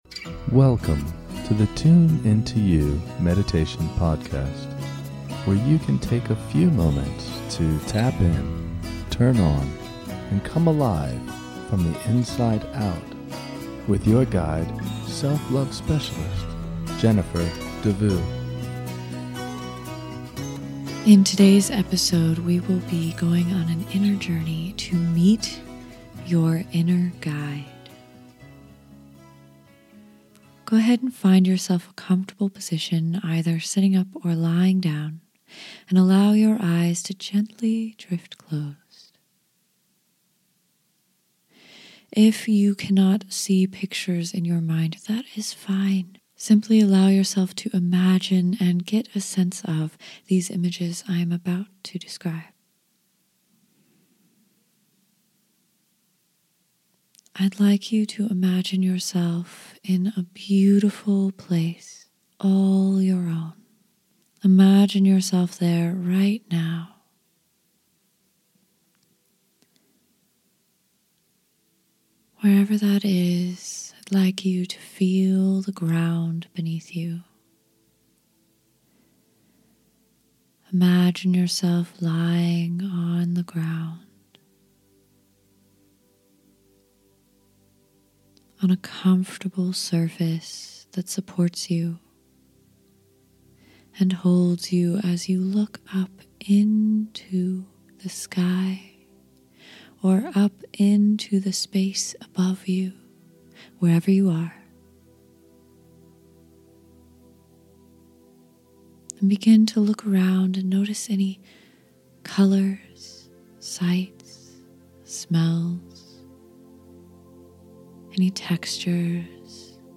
This is a guided meditation to meet your inner guide. This is a guided visualization.